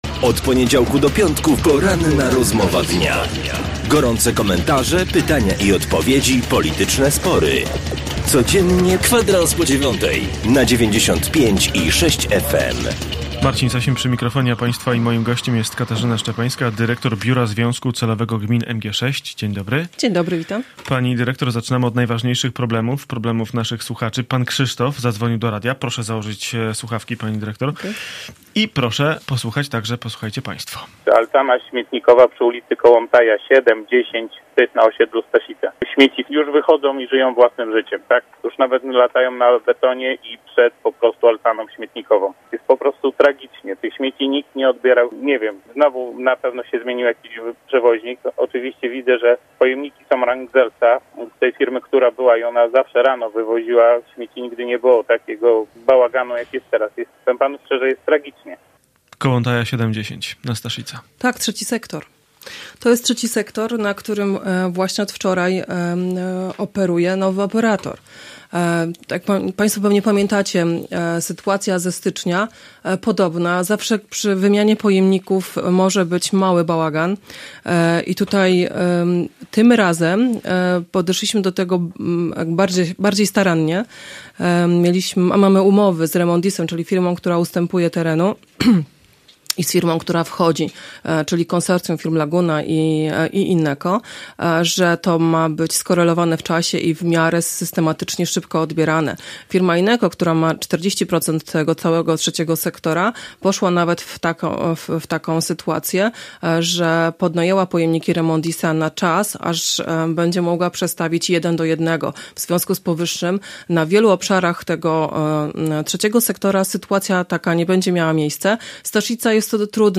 Gość na 95,6FM